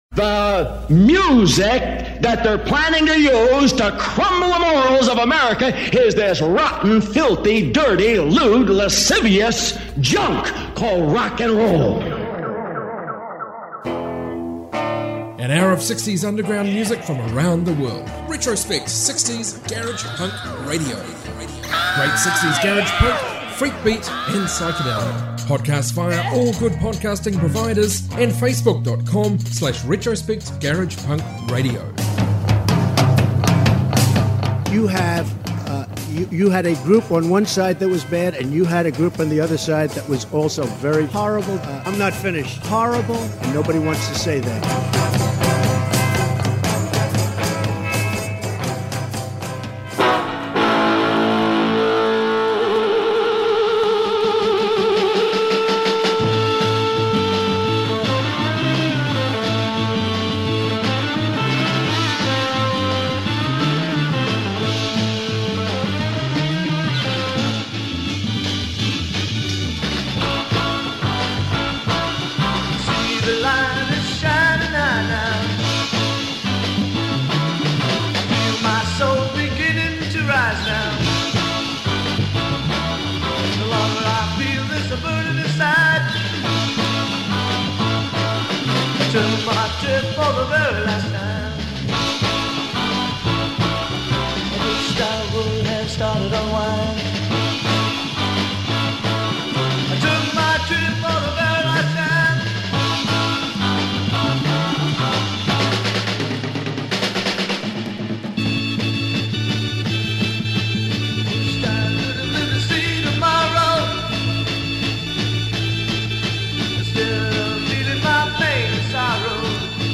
60s global garage